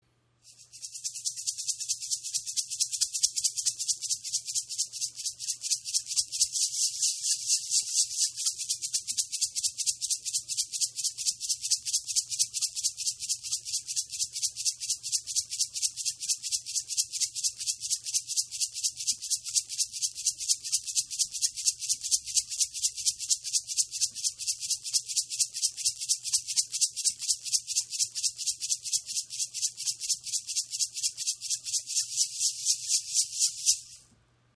Shipibo Zeremonie Rassel mit Adlerkopf
Der Sound dieser Rasseln ist klar und hell. Bei schamanischen Zeremonien und anderen Ritualen fungieren sie optimal als rhythmische Begleitung.